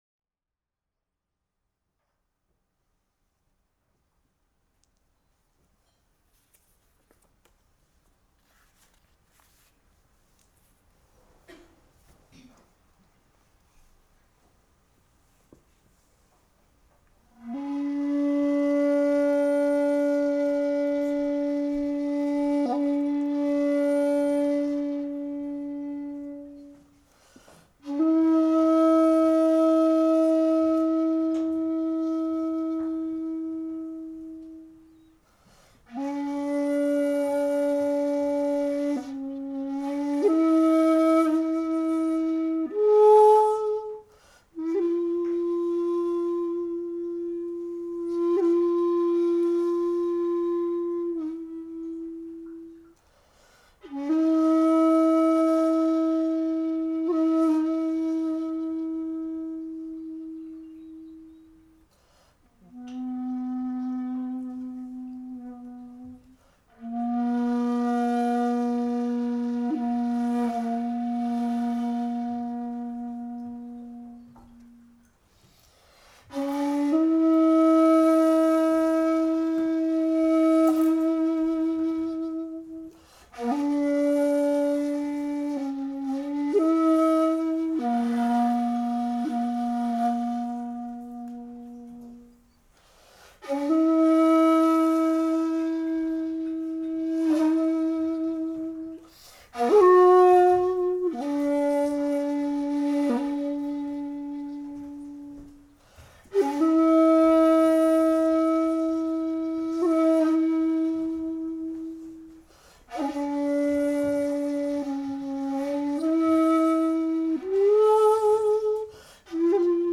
Пьеса "SanYa" .Запись с концерта
в Московском клубе ИНБИ